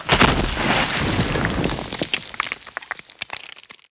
sounds / weapons / _boom / mono / dirt7.ogg
dirt7.ogg